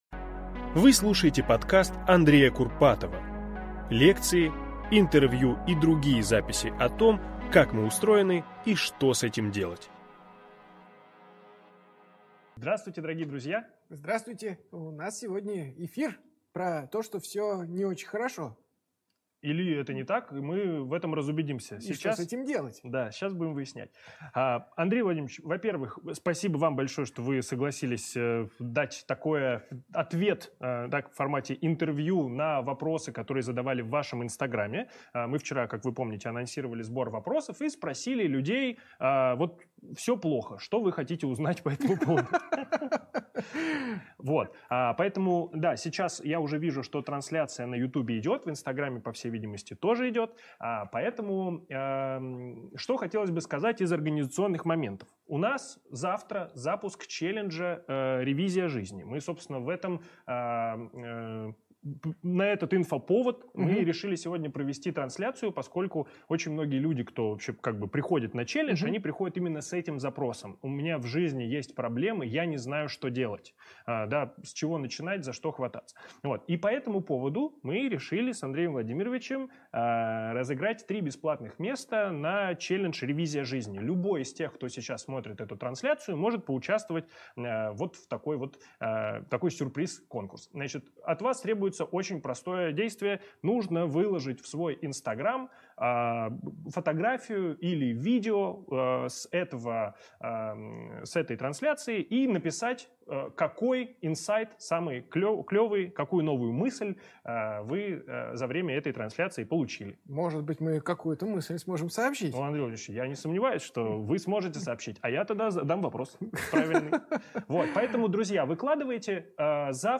Аудиокнига Всё плохо. Что делать?